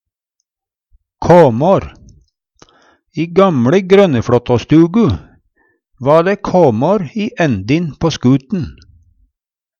kåmår - Numedalsmål (en-US)